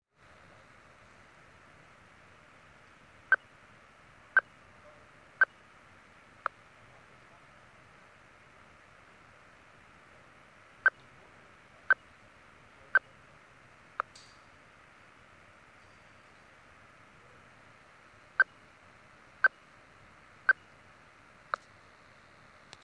Das Sendersignal hat drei deutliche Pieptöne und ein kurzen Nachhall von einem halben Ton.
Achtung: Der kurze Nachhall von einem halben Ton ist nur im Nahbereich zu hören und fehlt bei größeren Entfernungen völlig.